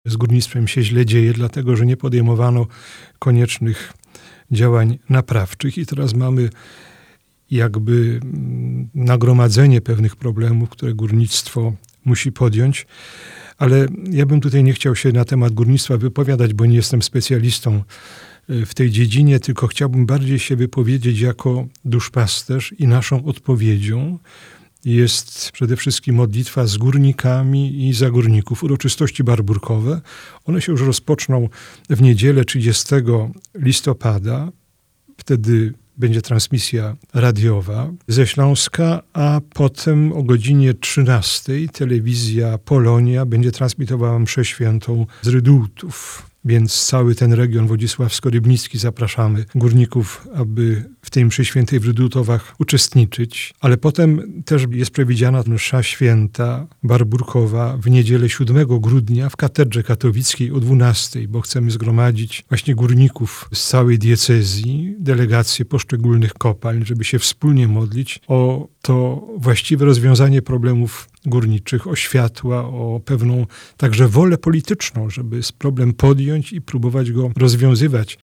Abp Wiktor Skworc zaprasza.